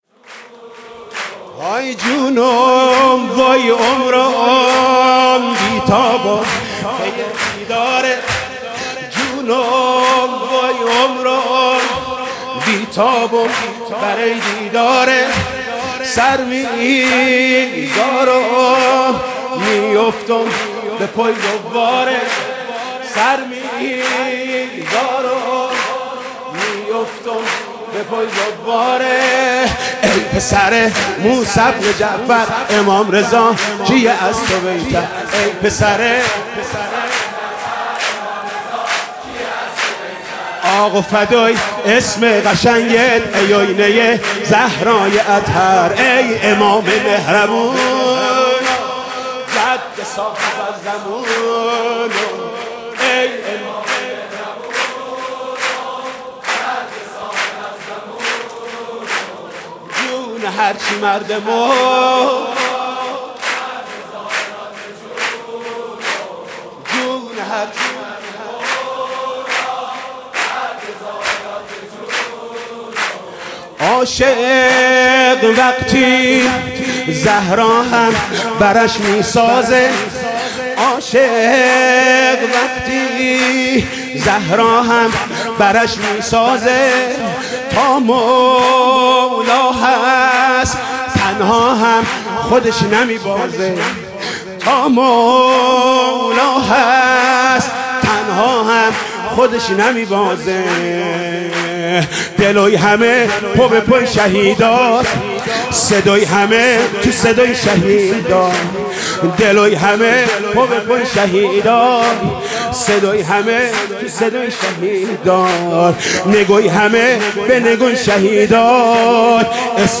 «میلاد امام رضا 1396» سرود: جونُم عُمرُم بی تابُم بَری دیدارت